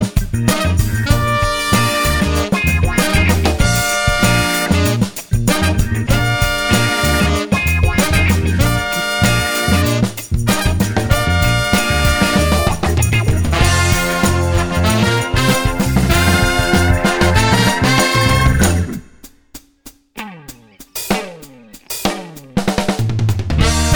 Live Pop (1960s) 2:25 Buy £1.50